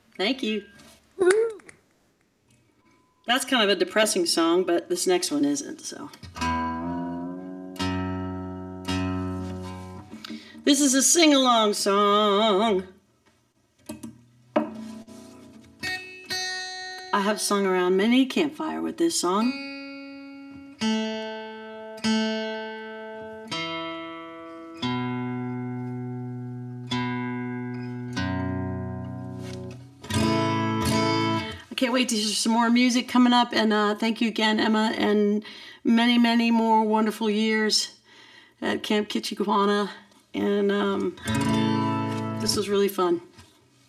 (captured from webcast)
06. interview (emily saliers) (0:43)